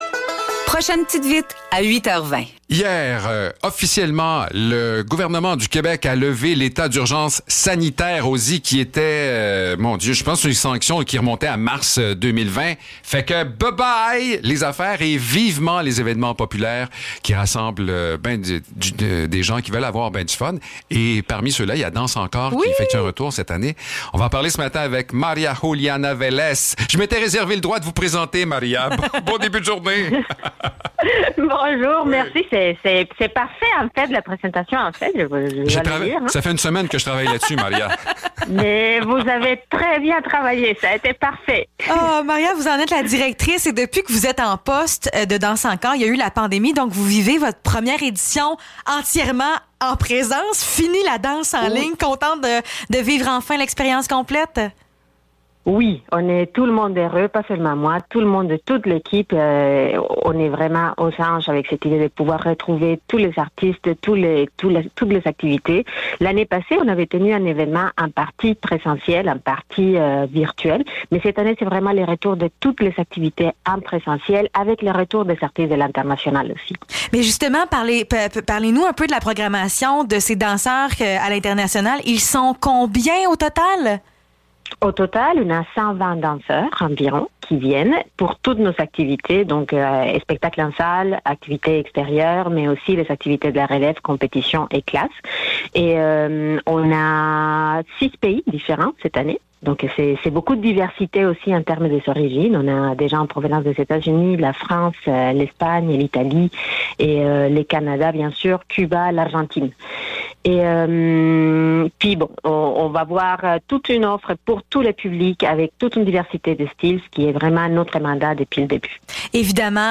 Entrevue pour le Festival DansEncore (2 juin 2022)
Entrevue-pour-DansEncore-2-juin-2022.mp3